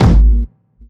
GS Phat Kicks 017.wav